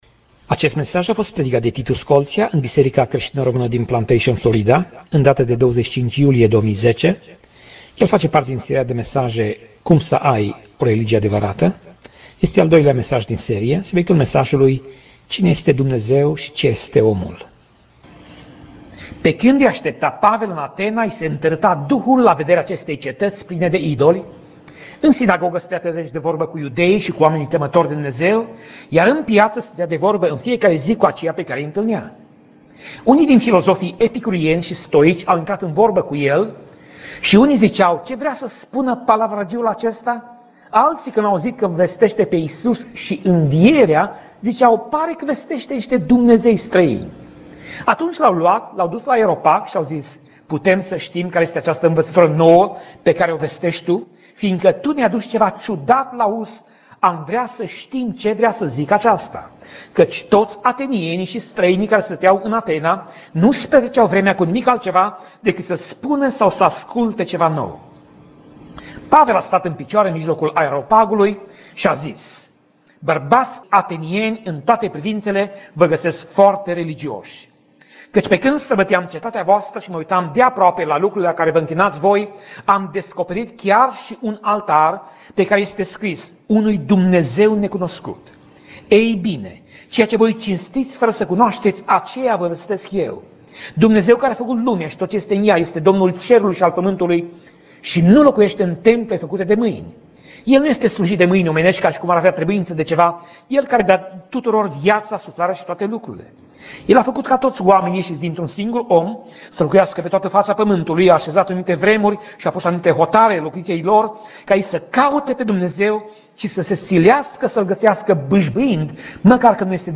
Pasaj Biblie: Faptele Apostolilor 17:16 - Faptele Apostolilor 17:34 Tip Mesaj: Predica